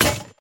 break.mp3